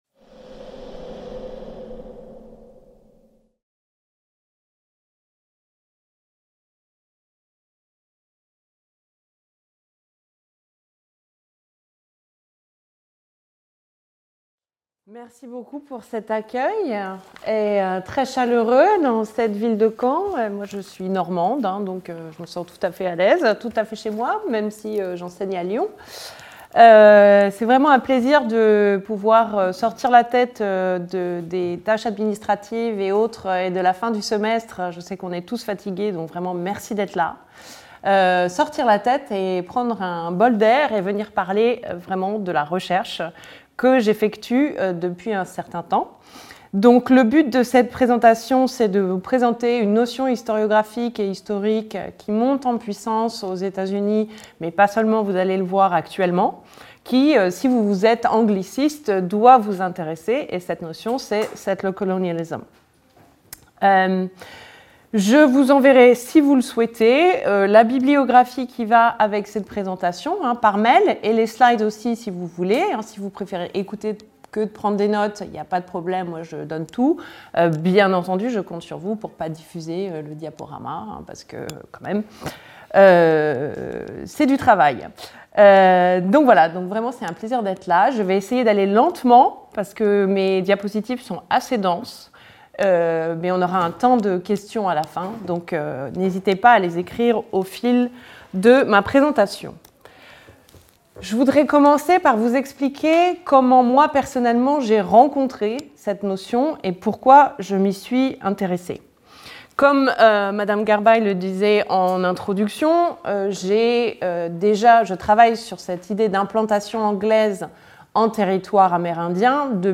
donnera une conférence intitulée